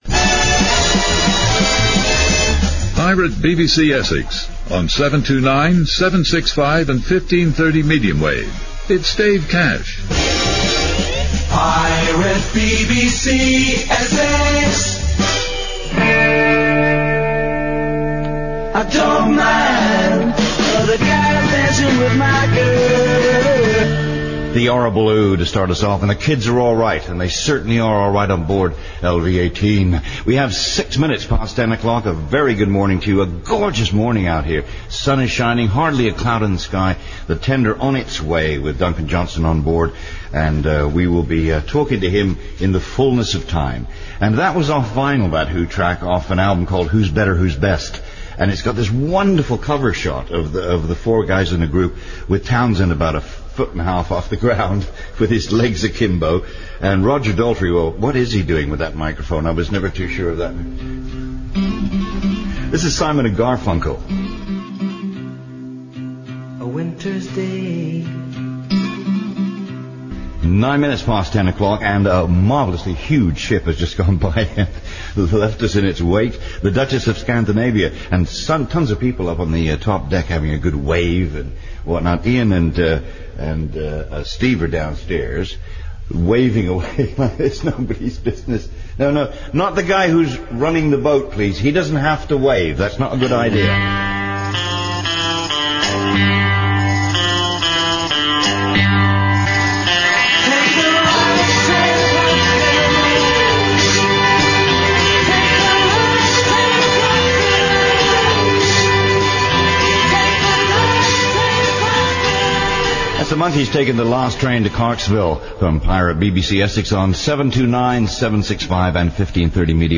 click to hear audio Dave Cash on Pirate BBC Essex, 17th April 2004 (duration 3 minutes 23 seconds)
To the readers of this website, it is probably his shows on Radio London which stick in the mind but to the generations that followed, Dave was always there with his warm, welcoming mid-Atlantic voice.